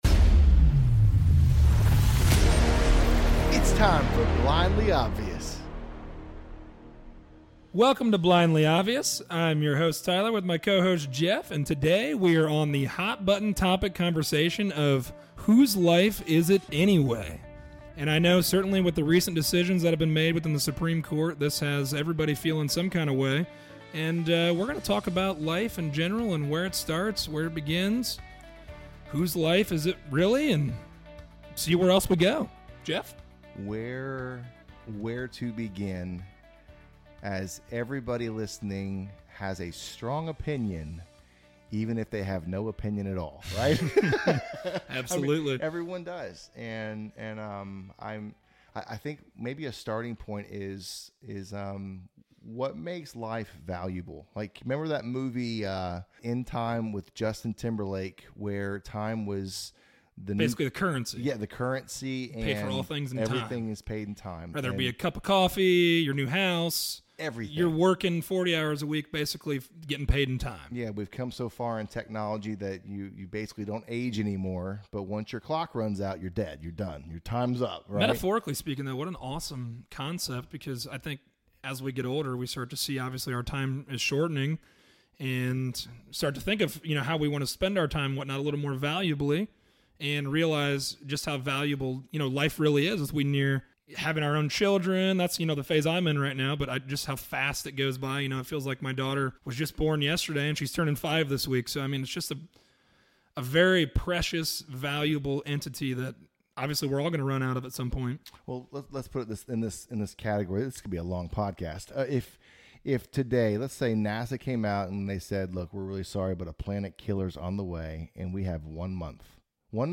A conversation about life and it’s sanctity. If we’re all guaranteed life, liberty, and the pursuit of happiness, how can we have liberty and the pursuit of happiness without first having life?